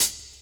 Closed Hats
Medicated Hat 3.wav